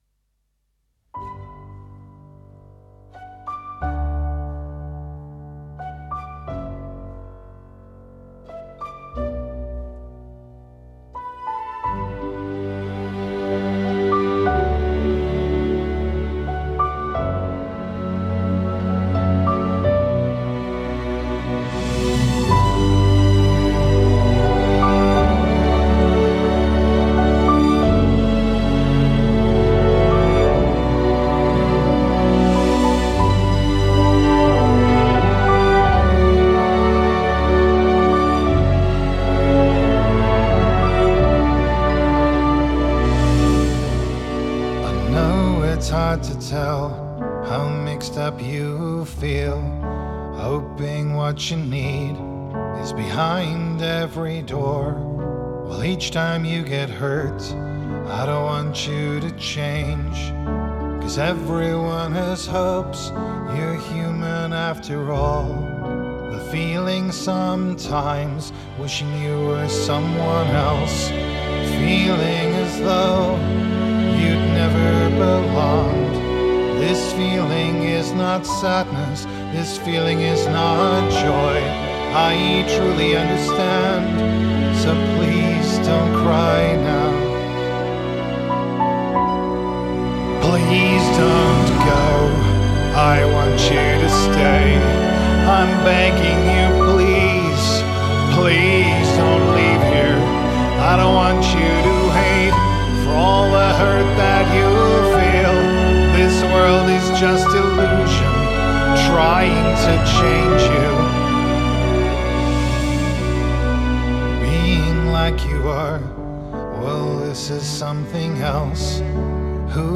arranged purely for voice and orchestra
Genre: Classical, Electronic